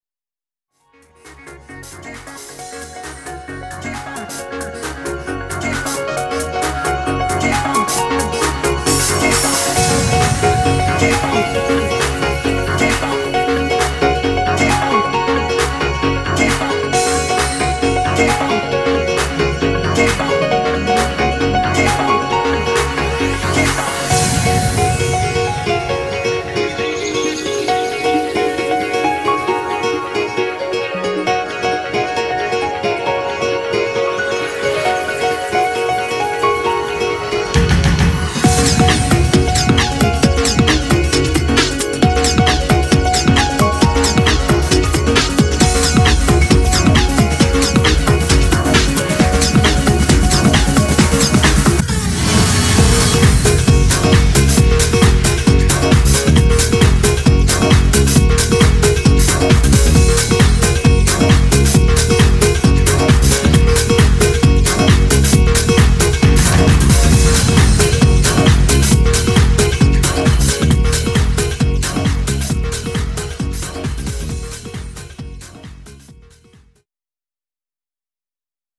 Dance, trance and beatology at its best!